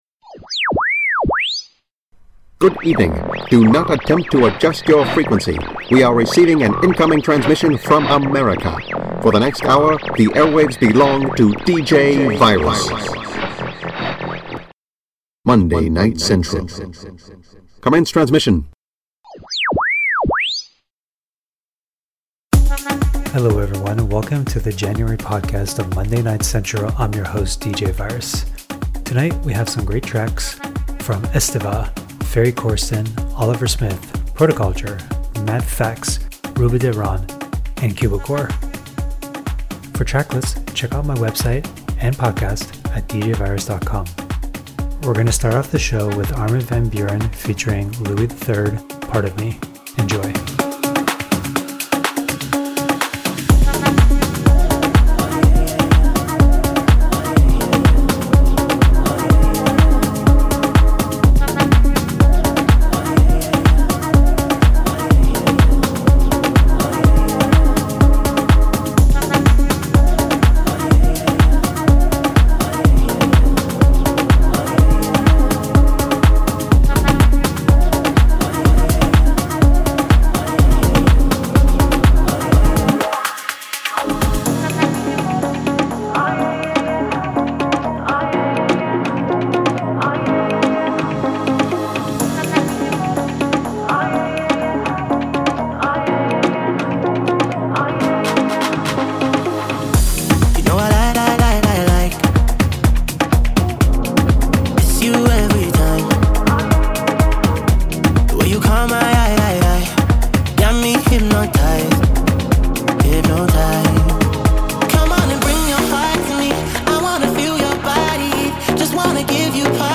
Spinning the latest in Trance and Progressive...